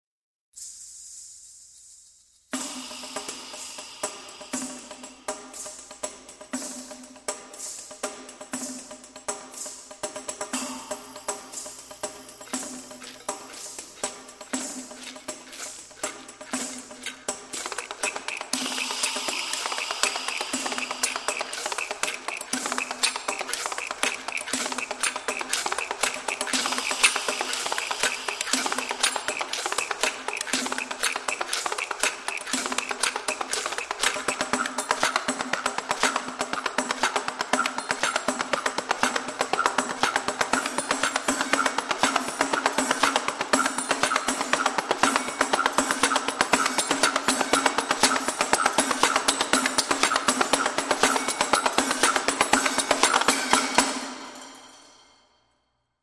Soundiron Little Epic Percussion是一款为Kontakt制作的采样音源库，它包含了一系列小型的管弦乐和民族打击乐器，用于补充Soundiron已有的史诗鼓音源库。Soundiron在同一个宏大的录音厅中，使用与其他许多打击乐音源库相同的技术，捕捉了它们的大部分乐器，所以Soundiron认为它们可以很好地与其他音源库相融合。
- 船钟
- 木蛙 木鱼
- 美洲原住民兔子鼓
- 一系列合奏打击乐器，如三角铁，沙锤，木棒，手指铙钹等